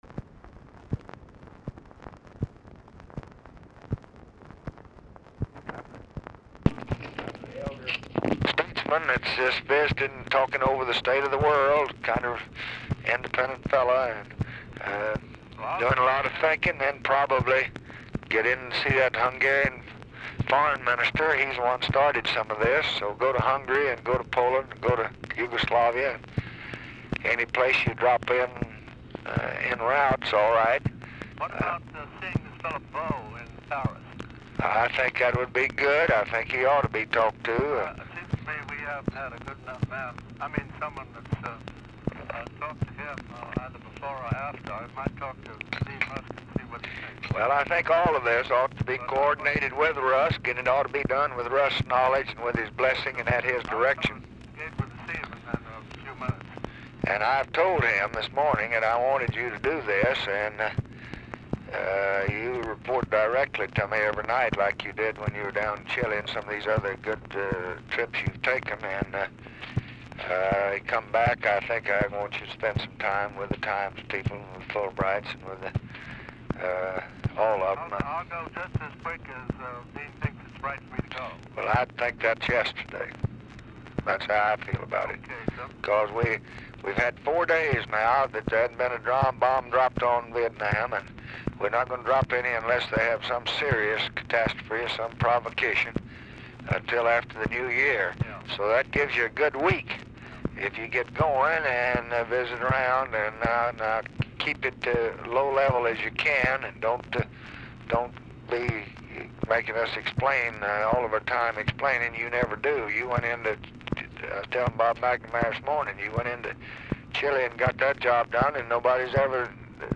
RECORDING STARTS AFTER CONVERSATION HAS BEGUN
Format Dictation belt
Location Of Speaker 1 LBJ Ranch, near Stonewall, Texas
Specific Item Type Telephone conversation